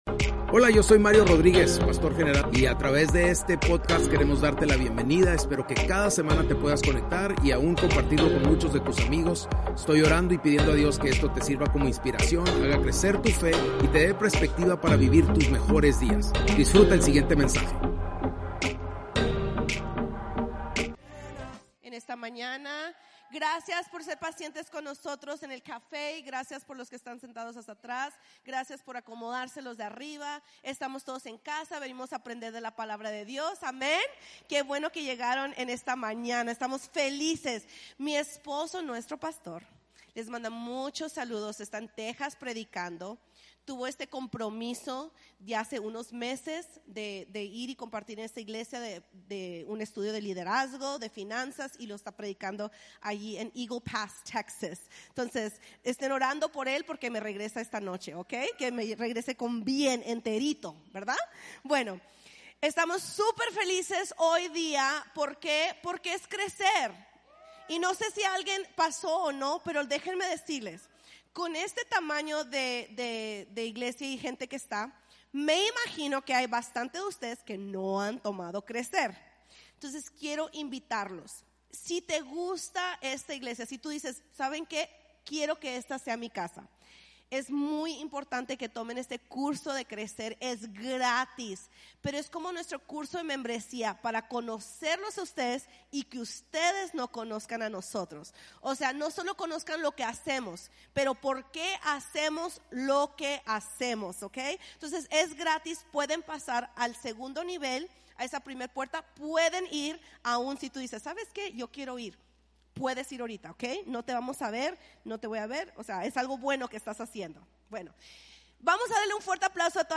Spanish Sermons